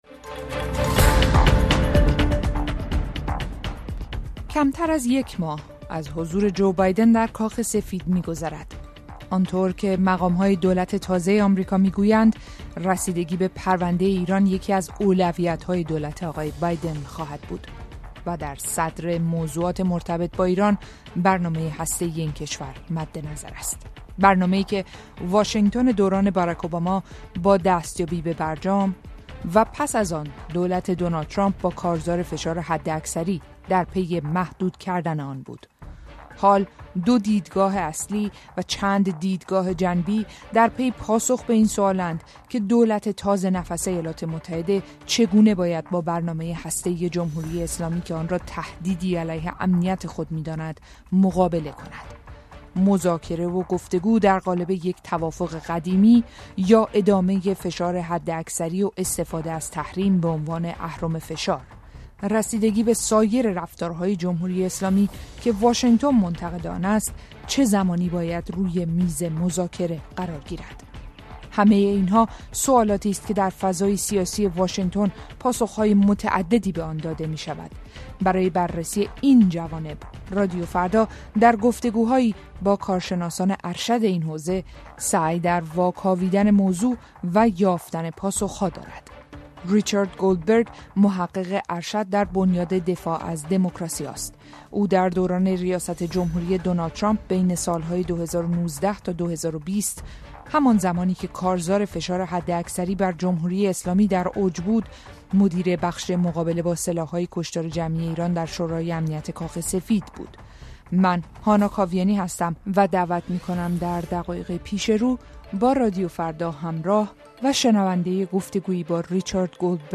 رسیدگی به سایر رفتارهای جمهوری اسلامی که واشینگتن منتقد آن است چه زمانی باید روی میز مذاکره قرار گیرد؟ رادیوفردا در گفت‌وگو با کارشناسان ارشد ایران سعی در واکاویدن موضوع دارد.